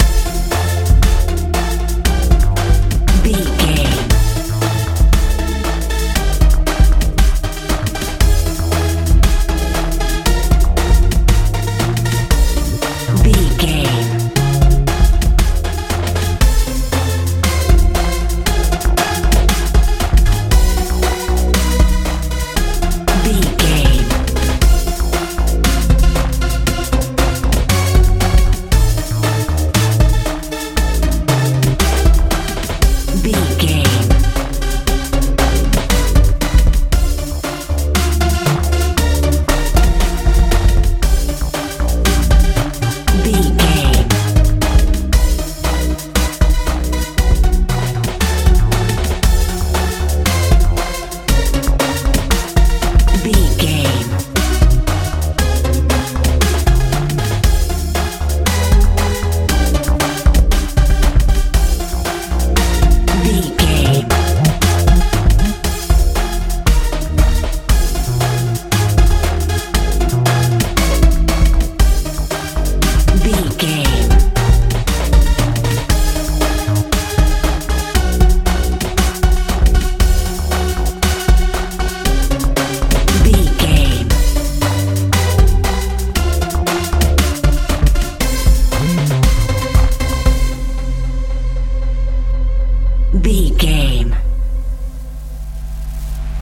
modern pop feel
Atonal
happy
synthesiser
bass guitar
drums
80s
strange
suspense